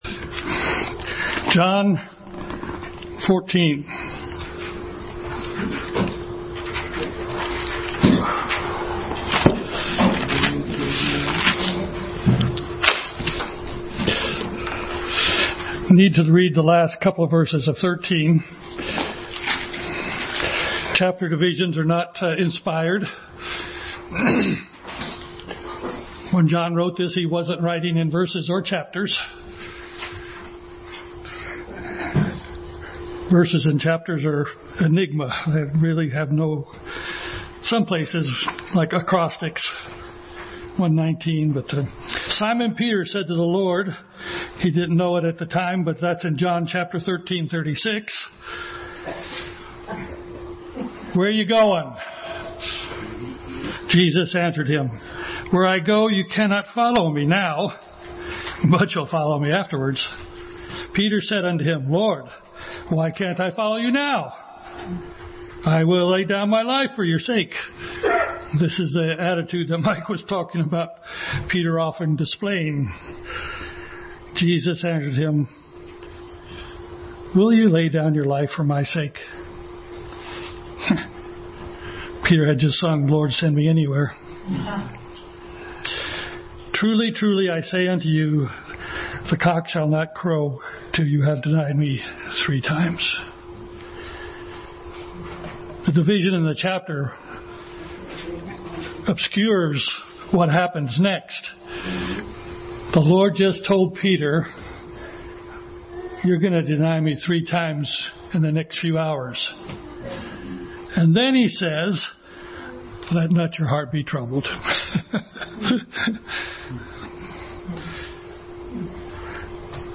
Passage: John 13:36-14:14, 2 Samuel 18:20 Service Type: Family Bible Hour